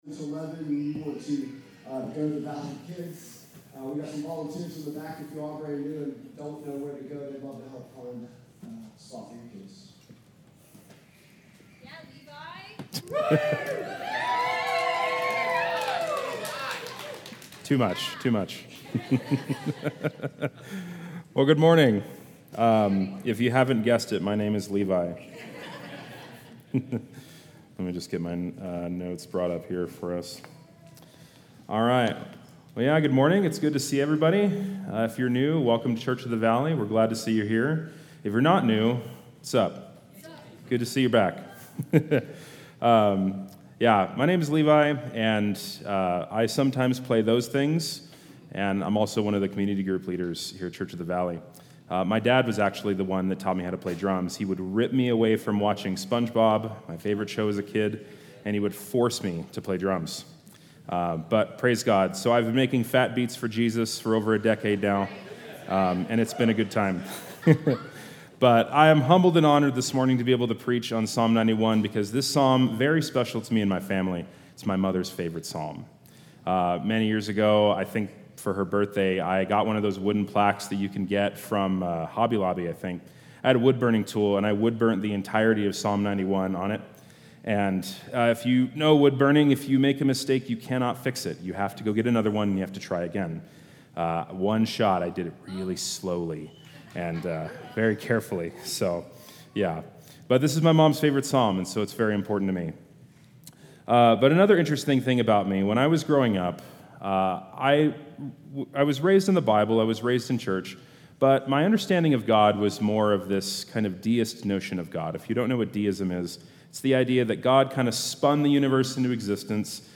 Vision & Values Meet Our Team Statement of Faith Sermons Contact Us Give Summer in the Psalms | Psalm 91 July 20, 2025